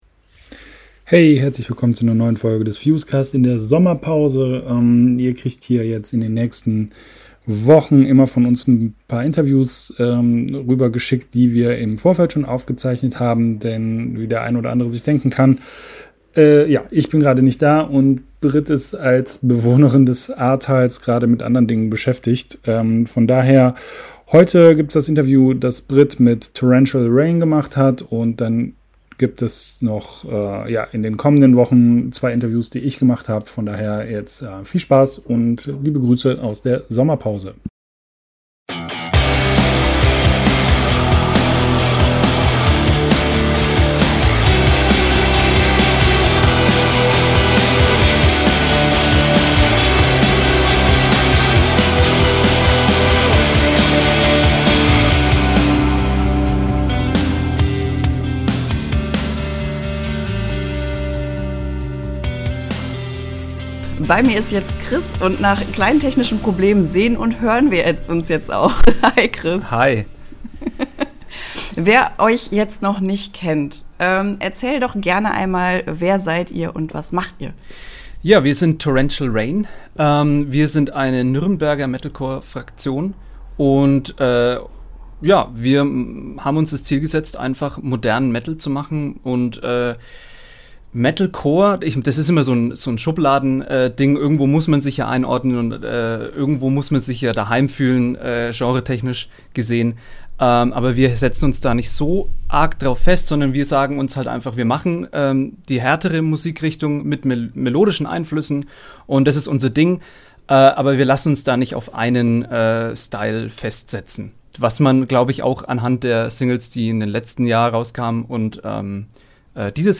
aber trotzdem haben wir ein paar Interviews für euch vorproduziert.